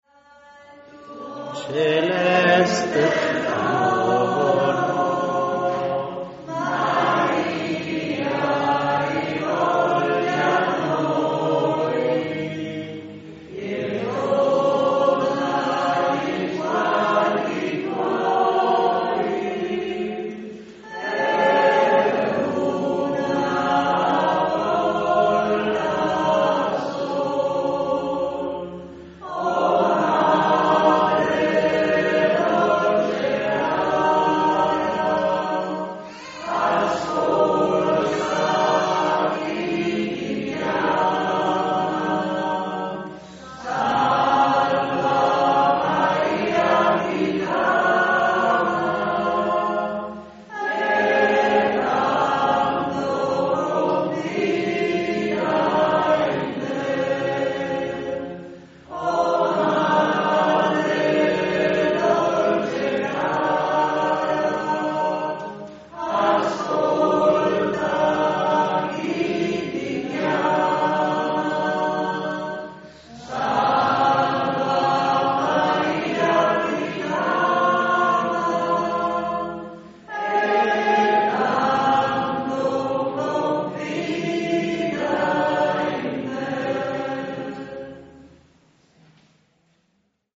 Festa Patronale di San Martino
BENEDIZIONE E CANTO DI CHIUSURA